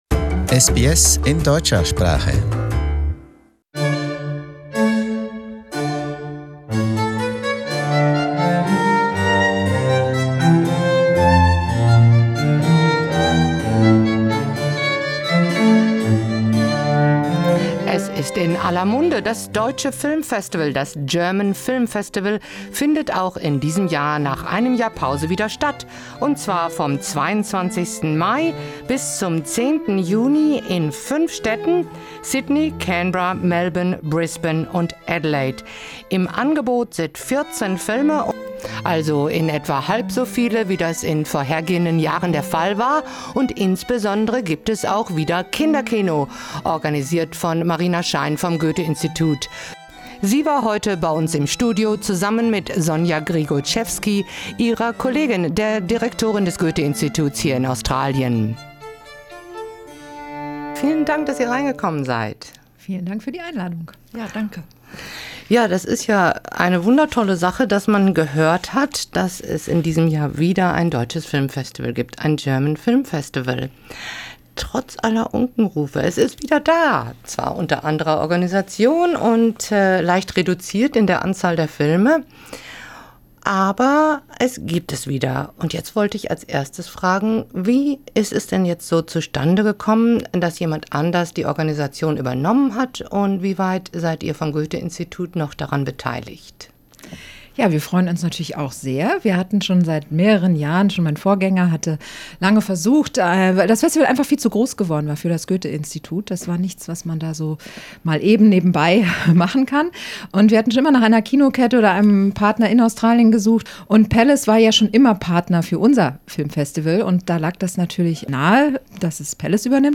Im Gespräch: Das German Film Festival unter neuer Regie